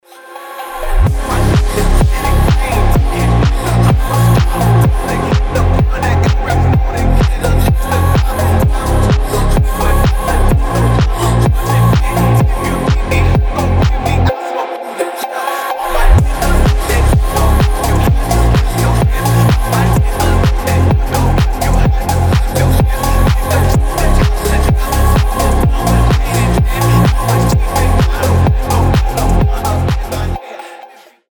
• Качество: 320, Stereo
атмосферные
фонк